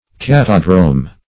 Search Result for " catadrome" : The Collaborative International Dictionary of English v.0.48: Catadrome \Cat"a*drome\, n. [Gr. kata`dromos race course; kata` down + dro`mos course.] 1.